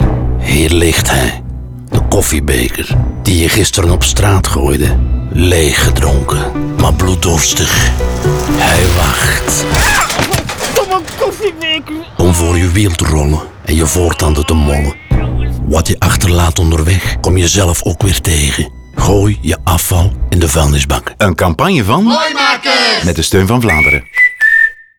Radiospot Koffiebeker